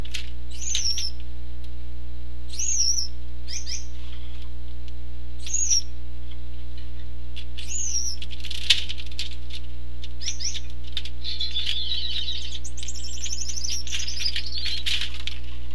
Audio clips were obtained from my personal breeding pairs.
Cock and Hen Calling Each Other (.mp3, .2 MB)
goulds_call_eachother.mp3